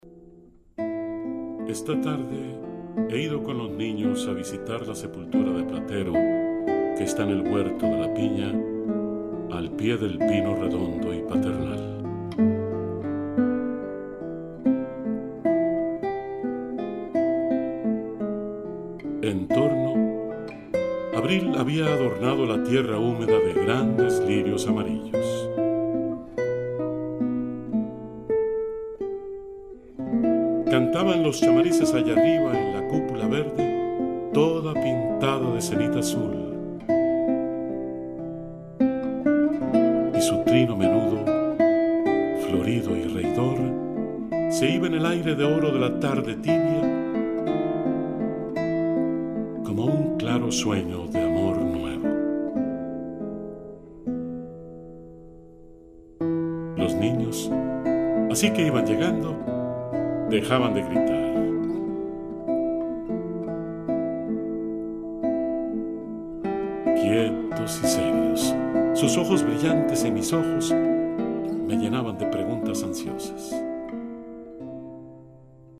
Narrator
Guitar